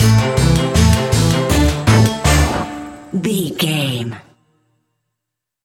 Aeolian/Minor
D
tension
ominous
eerie
synthesiser
drum machine
horror music